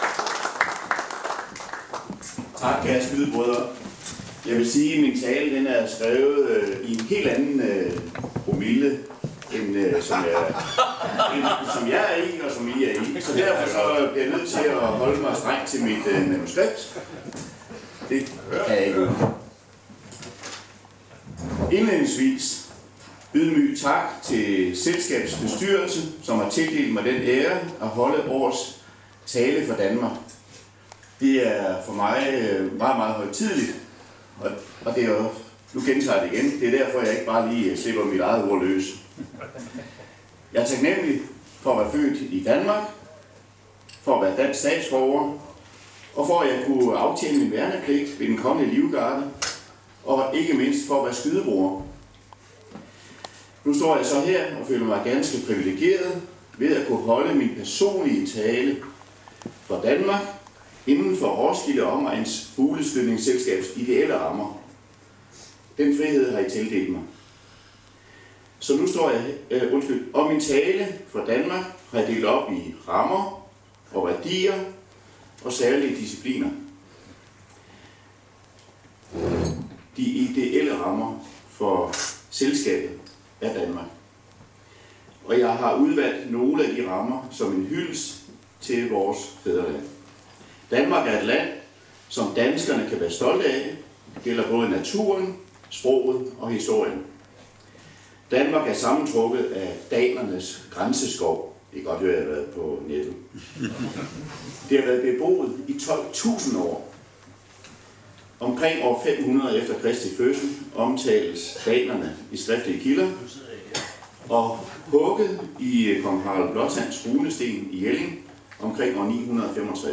tale til Danmark
blev videooptaget under fugleskydningen i 2024